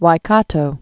(wī-kätō)